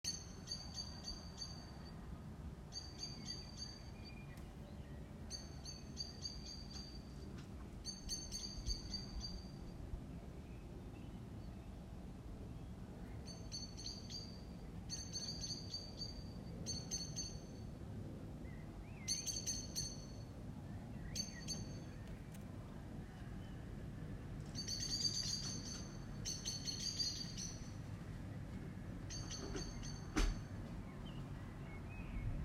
Oiseaux.m4a
KFiuXGOnm7d_Oiseaux.m4a